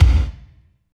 35.05 KICK.wav